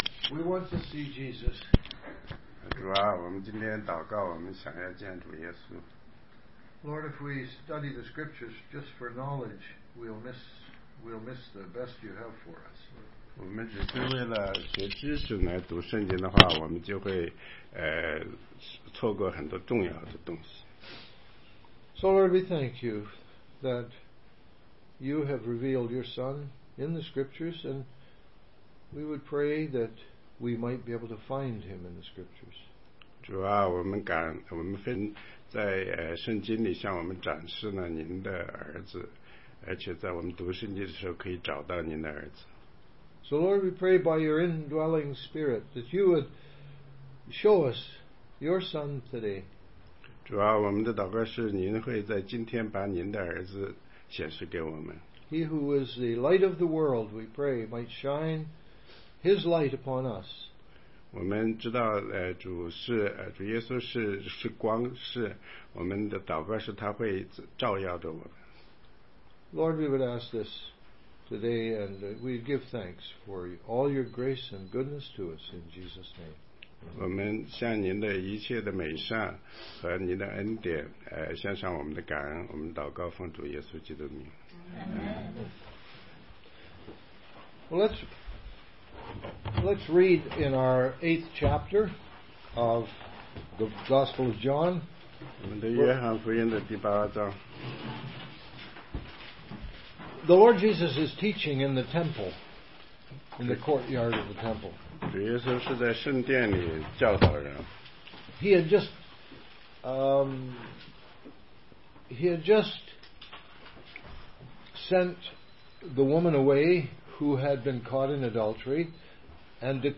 16街讲道录音 - 约翰福音8章21-30节